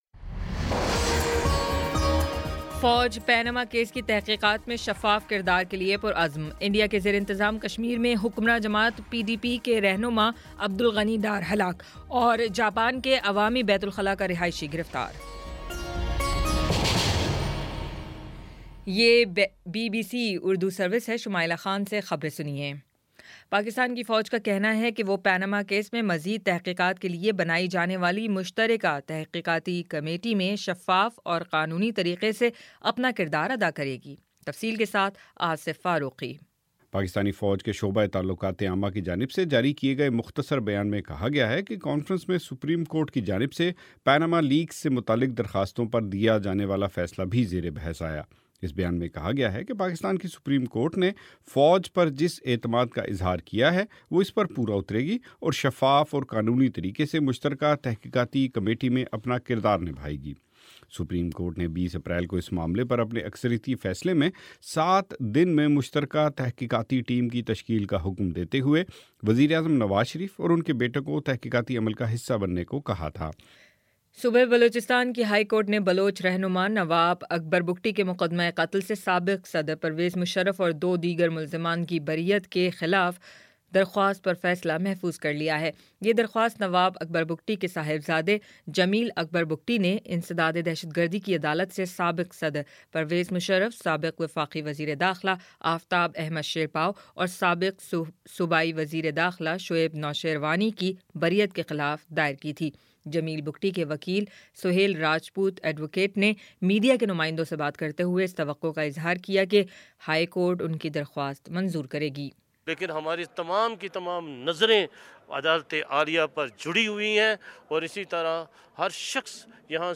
اپریل 24 : شام سات بجے کا نیوز بُلیٹن